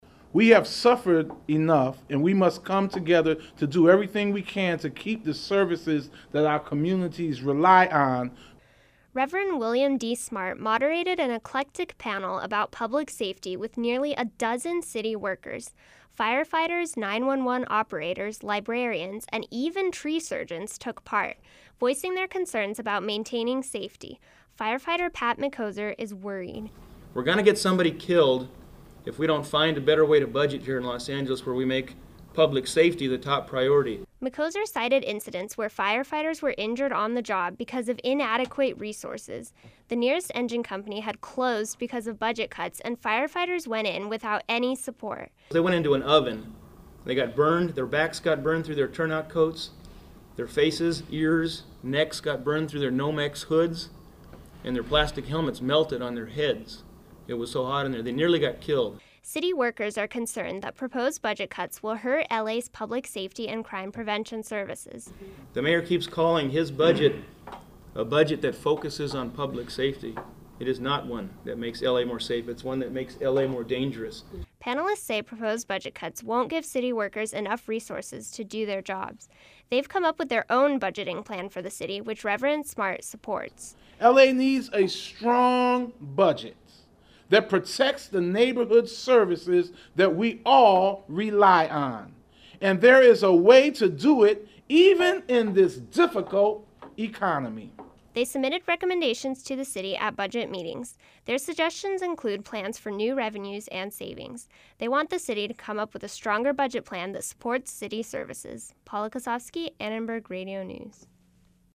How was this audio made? Los Angeles city workers are concerned the mayor's proposed budget will make the city more dangerous. They met at City Hall today for a panel discussion. budget_forum.mp3